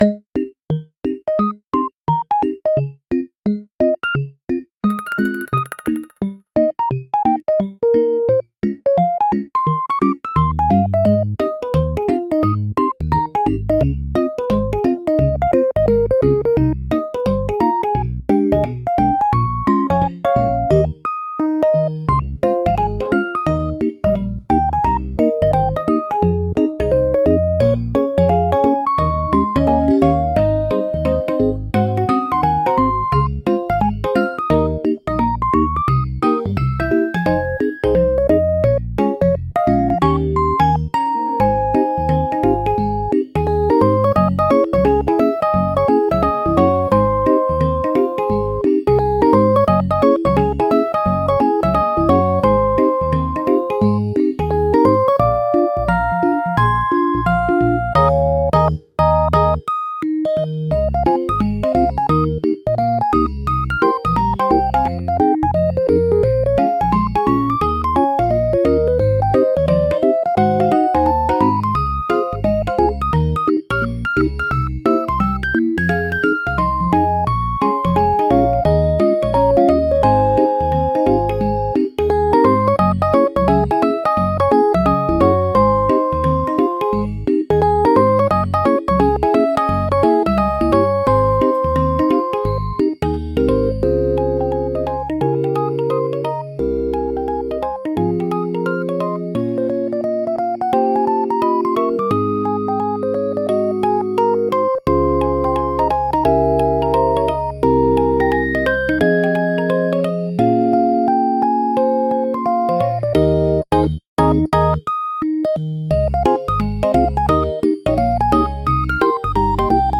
ピコピコ軽やか／ゲーム風BGM